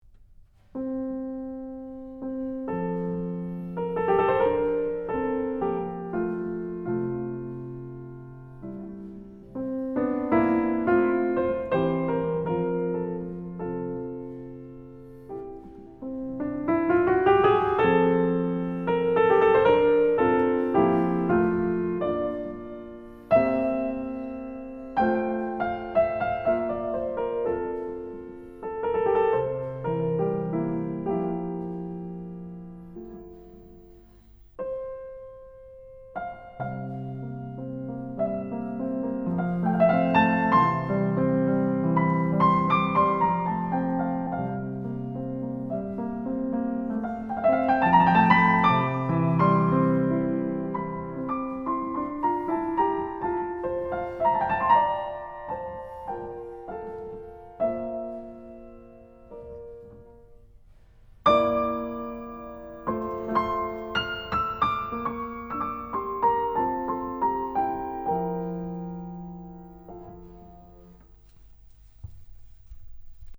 The second movement is in F-Major, which is a key that Beethoven uses often for serene, pastoral melodies.
So here is the beginning of the second movement of our sonata: although not as utopian in character as the theme from the Pastoral Symphony, it has warmth and is very, very beautiful: